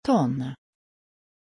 Pronuncia di Toine
pronunciation-toine-sv.mp3